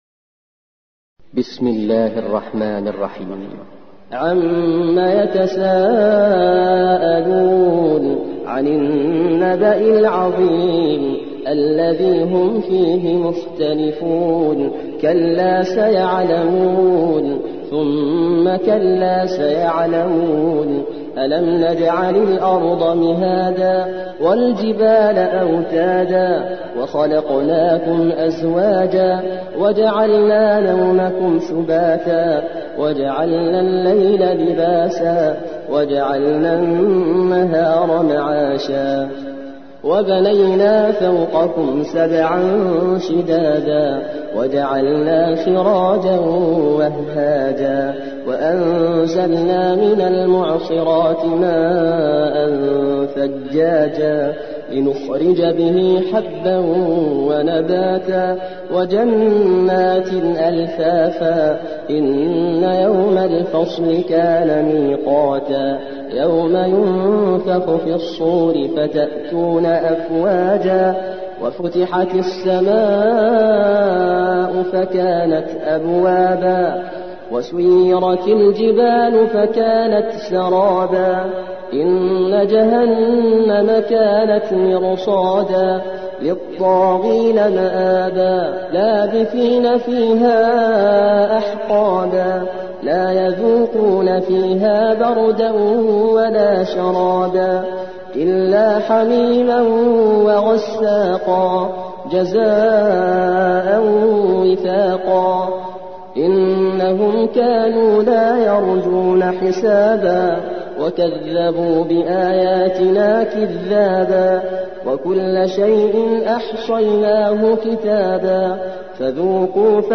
78. سورة النبأ / القارئ